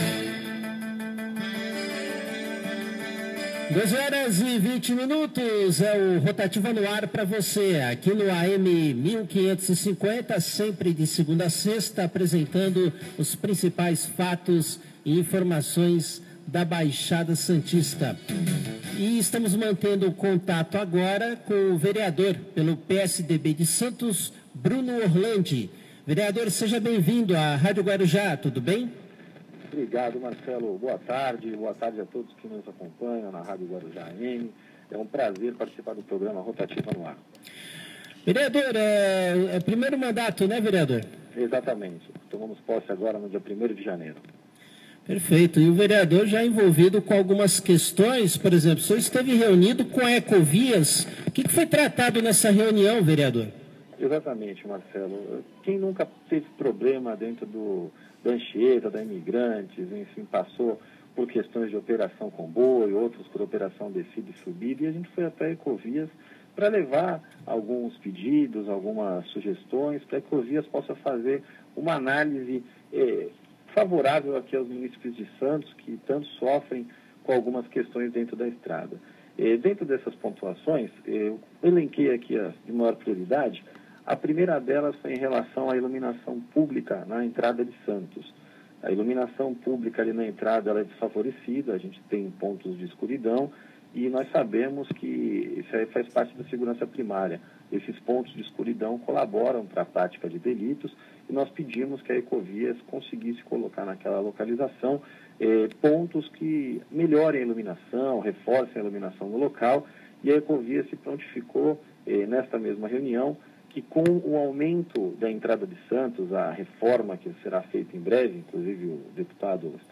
Entrevista Rádio Guarujá AM - Bruno Orlandi
Entrevista-Bruno-Guaruja-AM-3.mp3